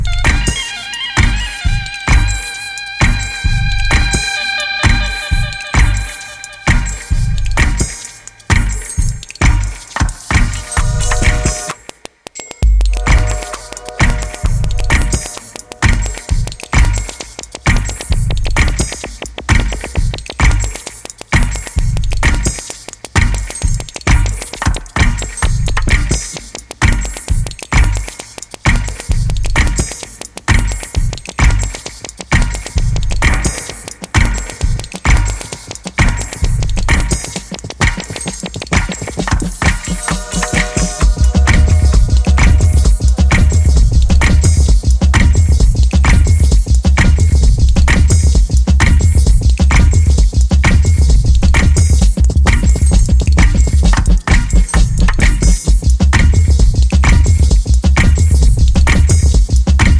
dub analog studio pressure on the B side.